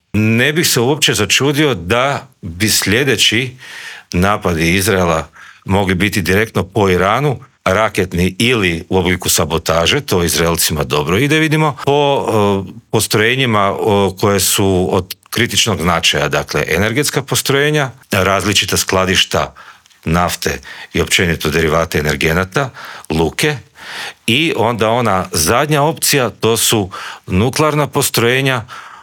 Intervjua Media Servisa